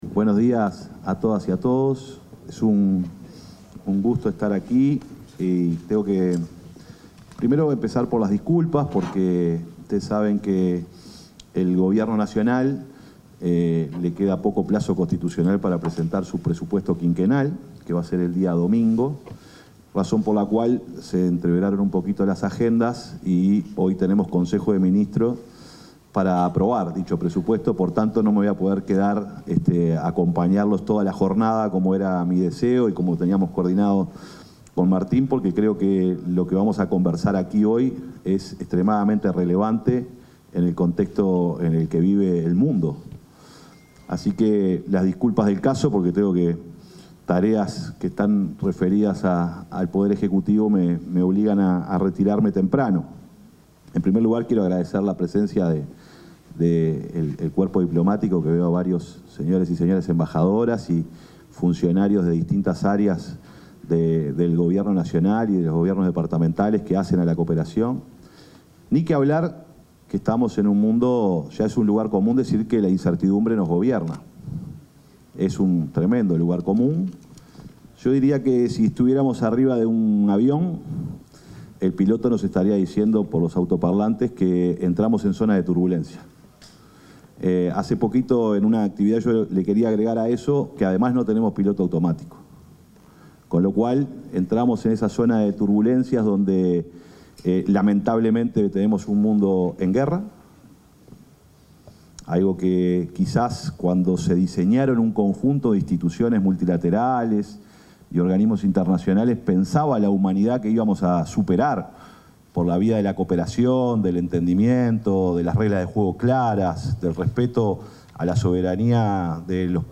Palabras del secretario de Presidencia, Alejandro Sánchez
El secretario de la Presidencia, Alejandro Sánchez, se expresó en el evento De Sevilla a la Acción.